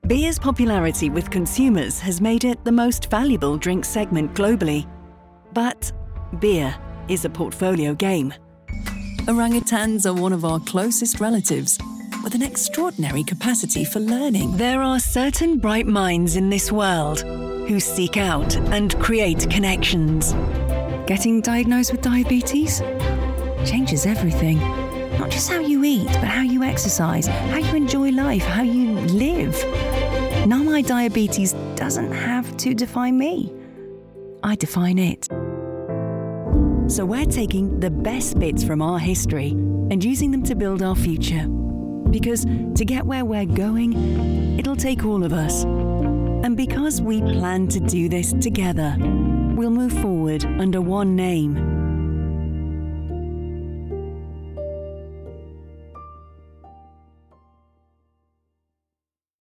Female
Bright, Confident, Corporate, Engaging, Friendly, Natural, Reassuring, Warm, Witty, Versatile
Northern (native), Geordie (native), Neutral British (native) RP, Scottish, Liverpudlian, Southern.
Microphone: Neumann TLM103, Sennheiser 416,